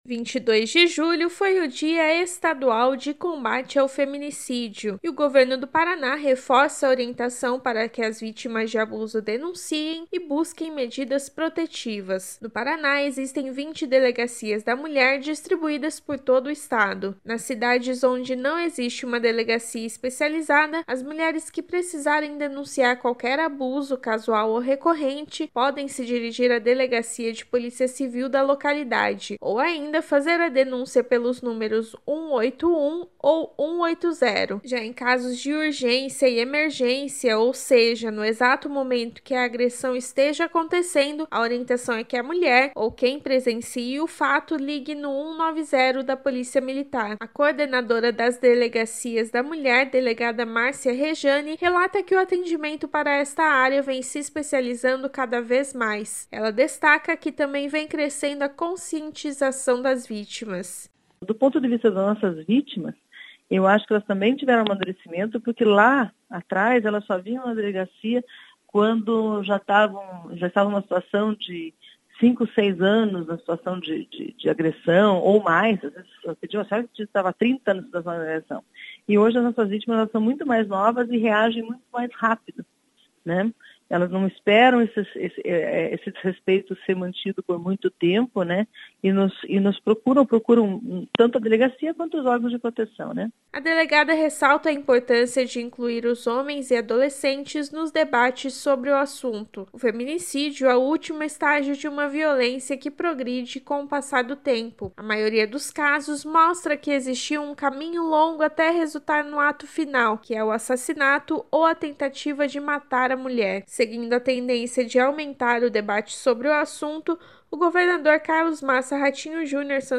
Ouça os detalhes dessa informação na matéria da repórter